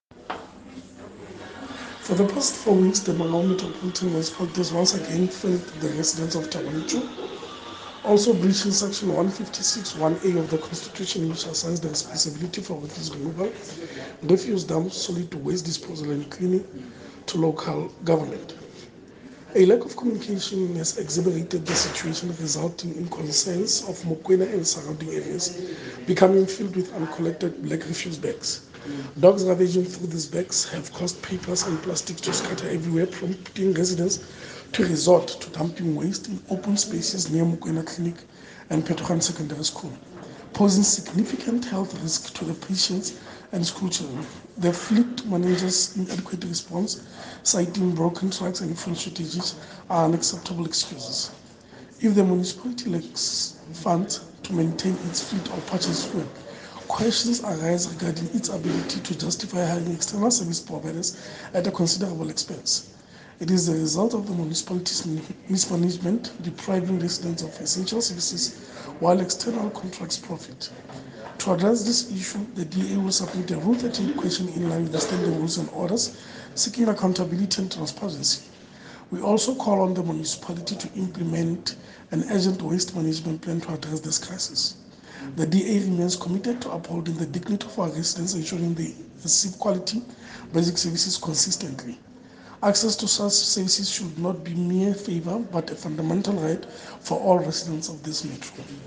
English and Sesotho soundbites by Cllr Kabelo Moreeng and